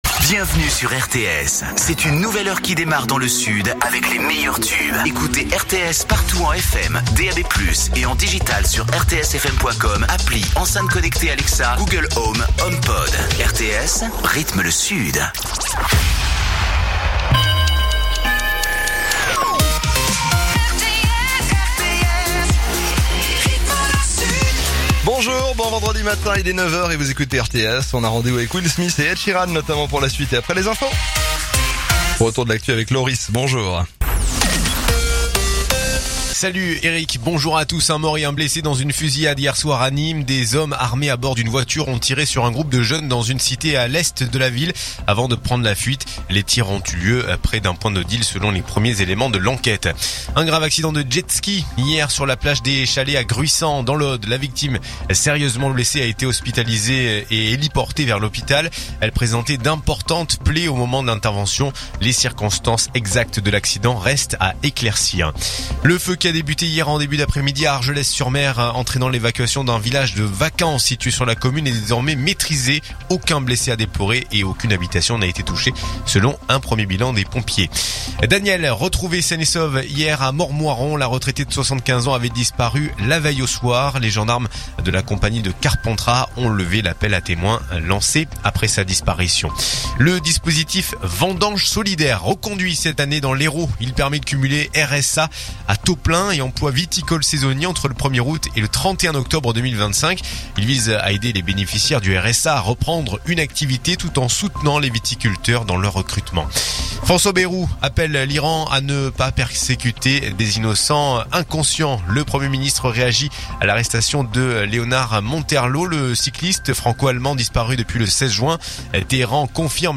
info_narbonne_toulouse_441.mp3